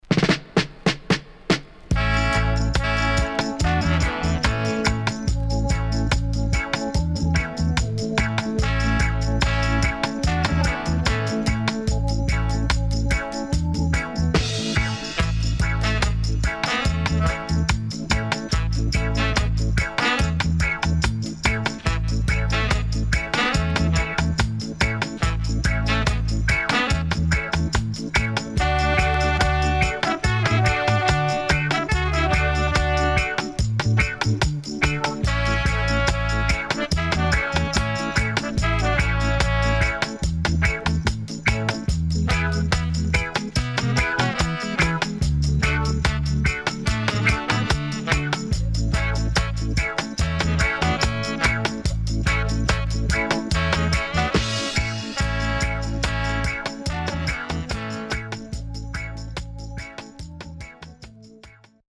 SIDE A:少しノイズ入ります。